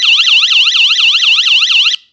Jing Yi elektromos kerékpár duda, egy szólamú, elemekkel, sárga
question_markTermékkör Elektromos duda
Erőteljes hangzás